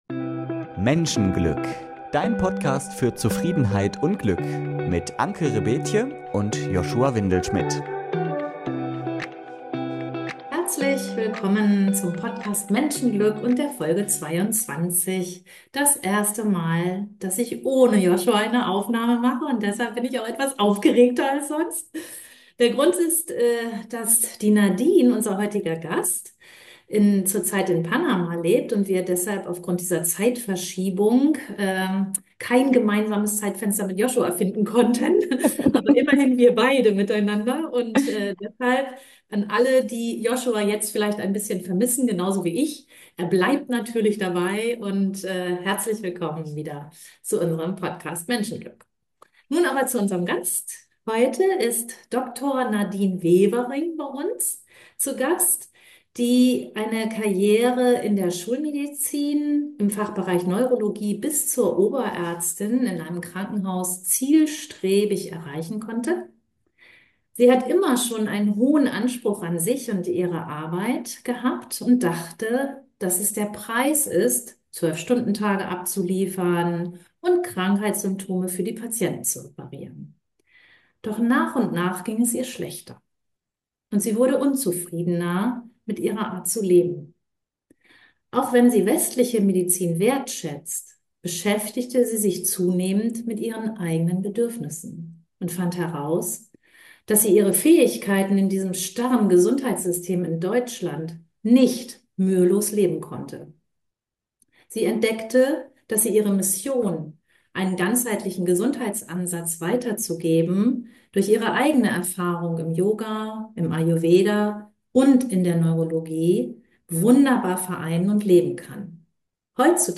ein Interview mit einer inspirierenden Persönlichkeit zu führen